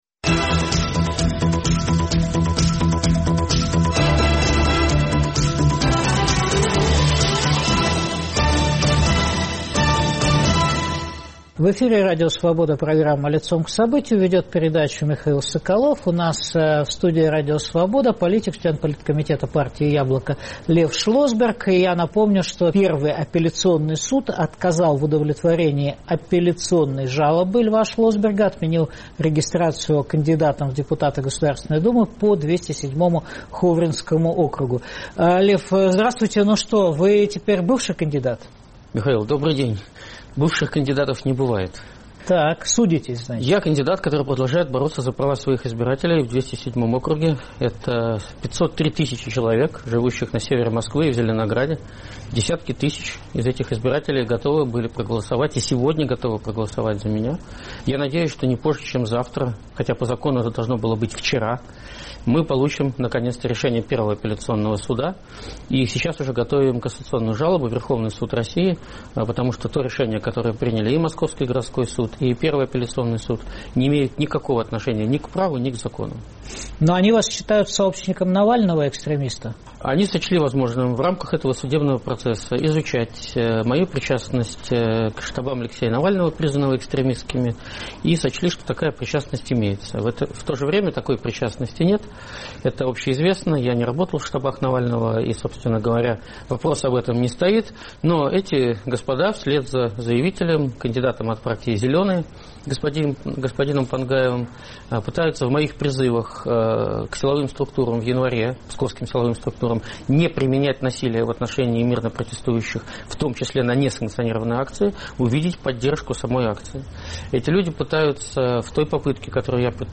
Какова альтернатива курсу "партии власти" на подачки и репрессии? В эфире член Политкомитета партии «Яблоко» Лев Шлосберг.